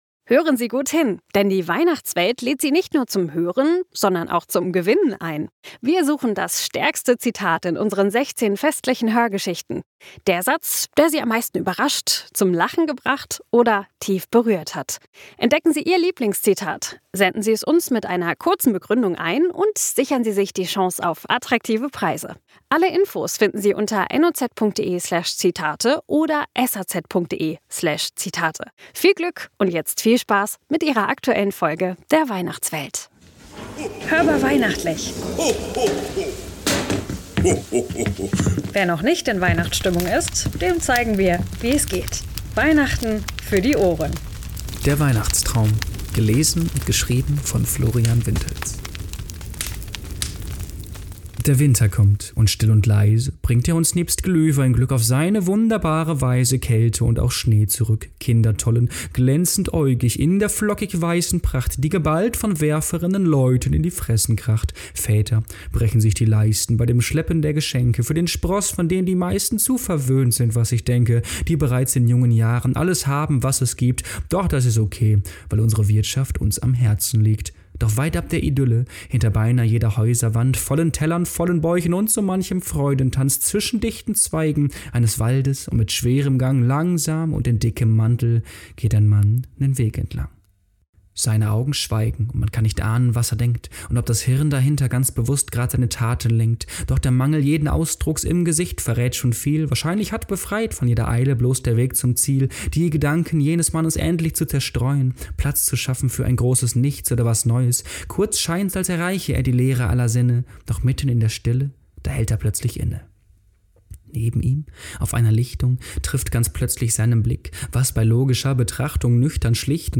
Poetry-Slam zu Weihnachten!